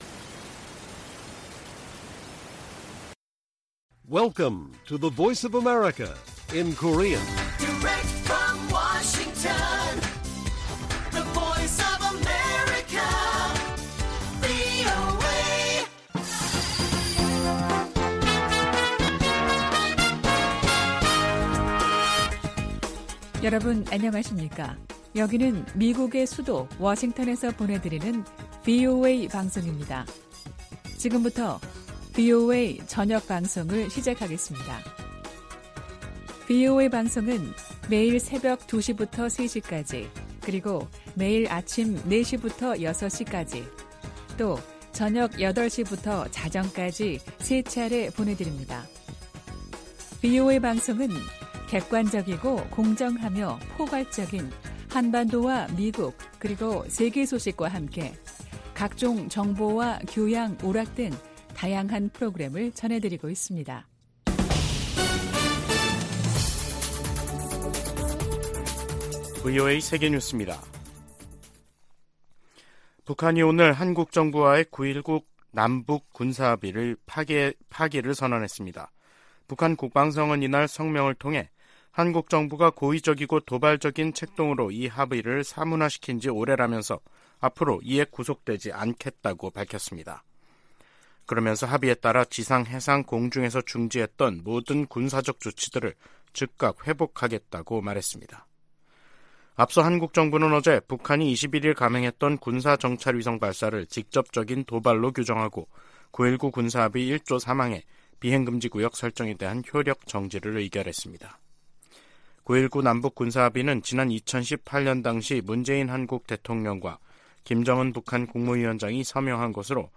VOA 한국어 간판 뉴스 프로그램 '뉴스 투데이', 2023년 11월 23일 1부 방송입니다.